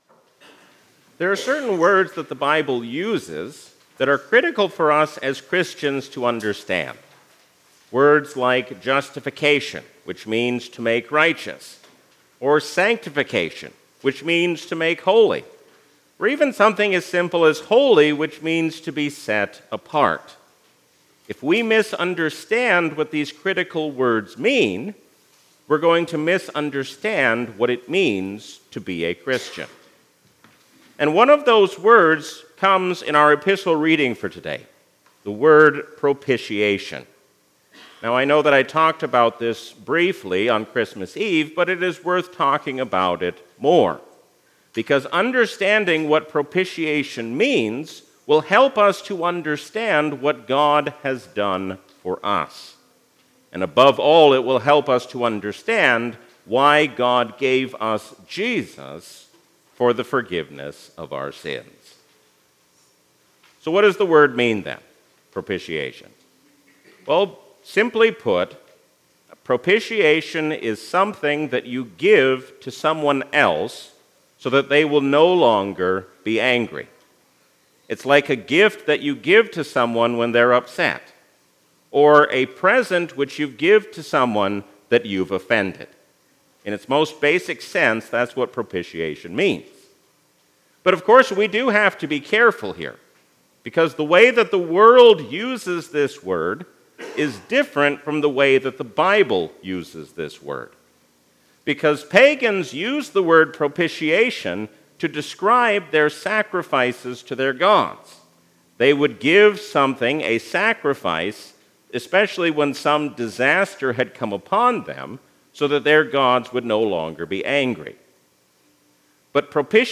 A sermon from the season "Christmas 2024." Jesus is the reason for the season because He has come to be our Brother and our Savior.